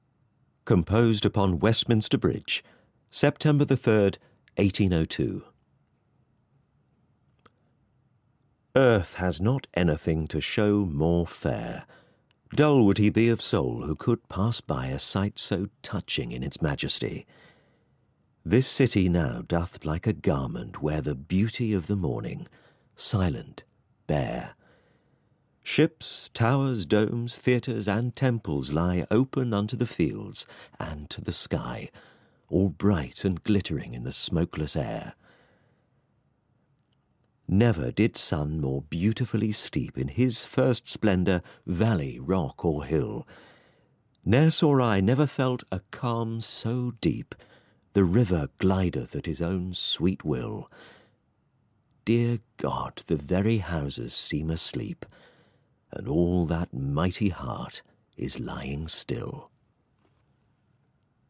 BBC News Online asked me to record Wordsworth's famous sonnet for a story about the Museum of Dockland's exhibition celebrating London's bridges.